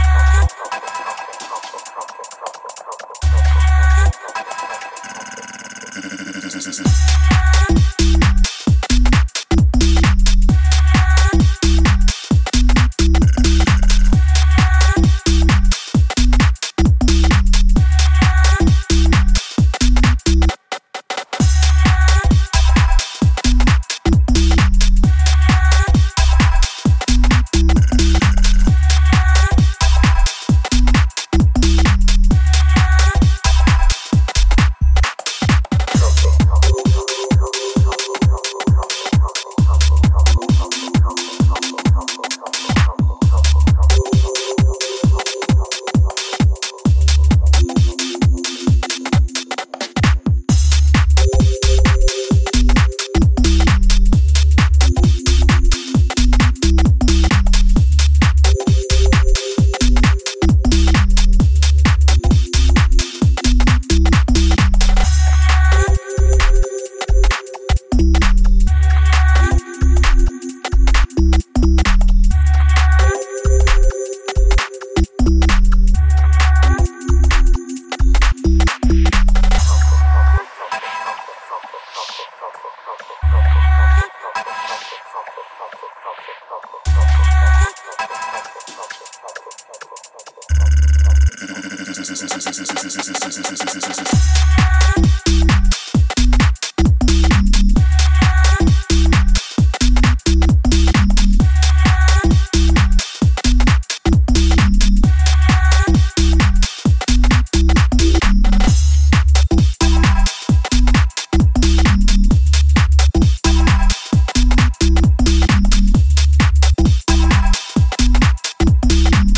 an ice-cold stepper